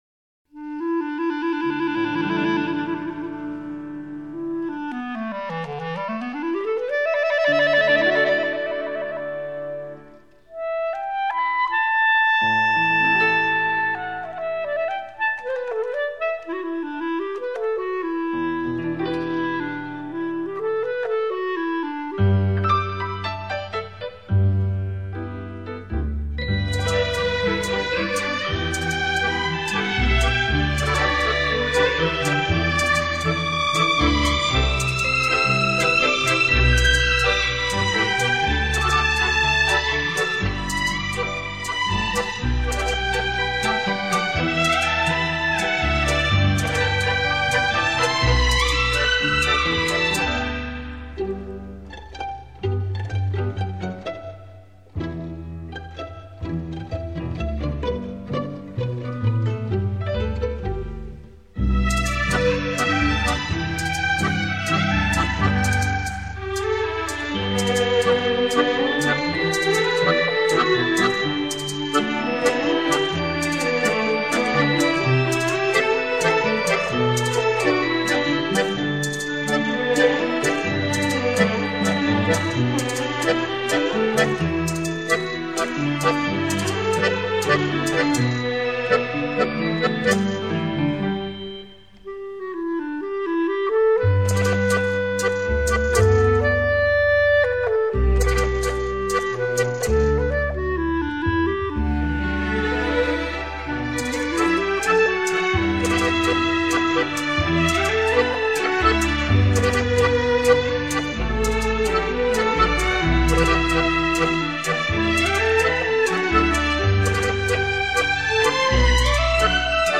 江南乐曲经典 精心编曲 用心之作
发烧精品 一曲曲动人旋律
轻音乐